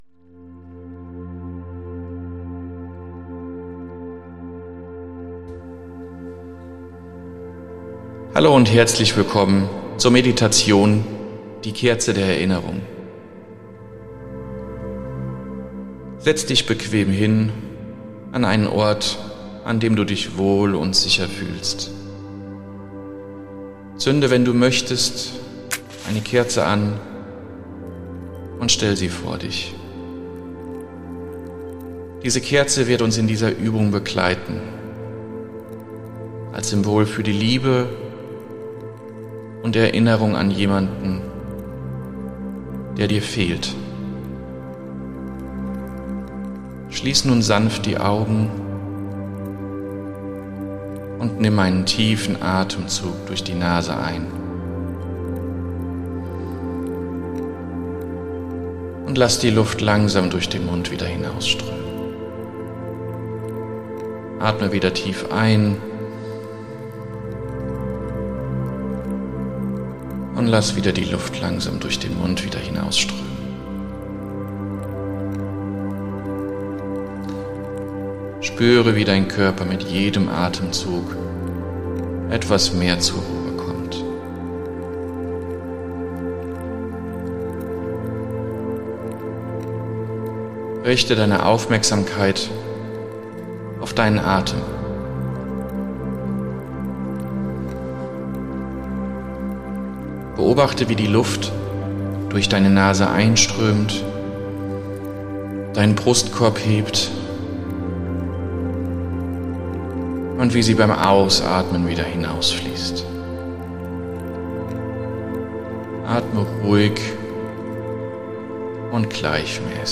Begleitet von sanften Klängen führt